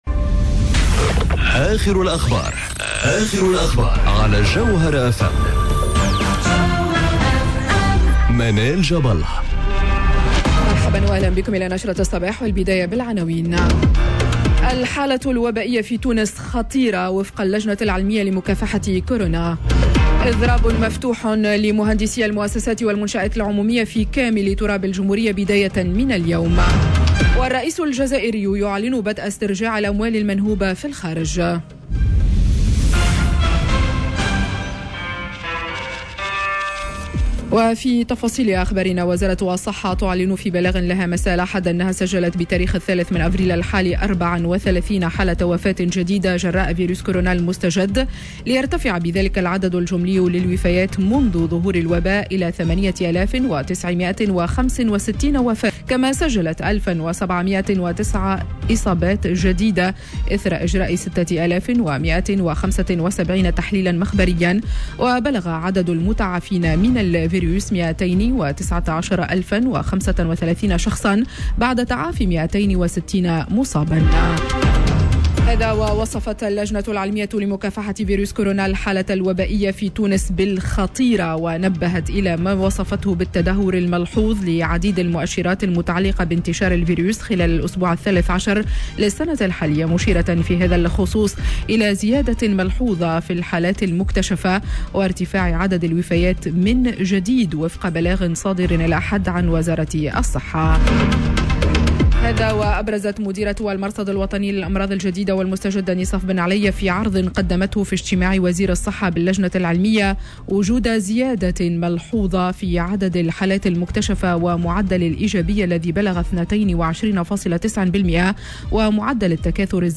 نشرة أخبار السابعة صباحا ليوم الإثنين 05 أفريل 2021